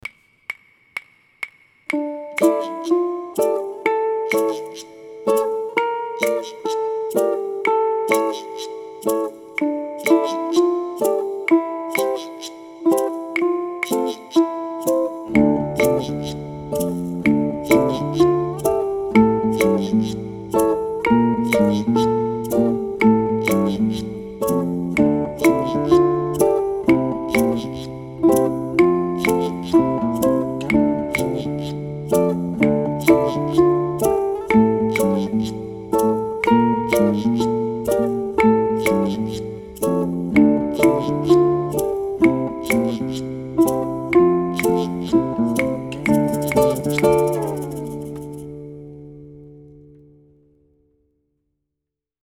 Now you can celebrate those impossible dreams with a song, the brief but swashbuckling Pie in the Sky.
Work towards a tempo goal of allegro, about 138 BPM.
The Reggae strum, studied in Sinner Man, is recommended for Pie in the Sky.
ʻukulele
Once the melody is learned, practice the chords with a Reggae strum.